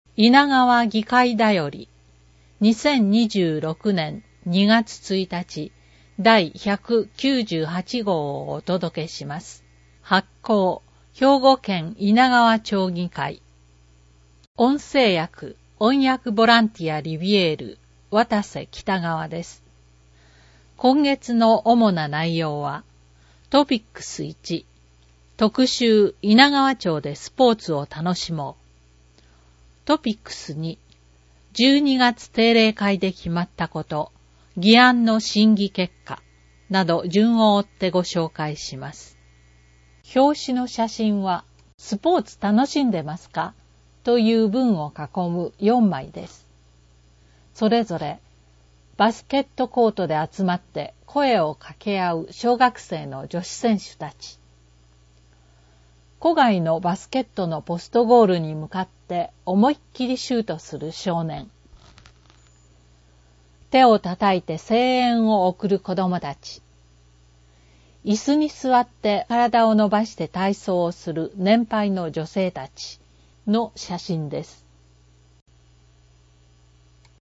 制作は猪名川町社会福祉協議会 音訳ボランティア リヴィエールの方々の協力によるものです。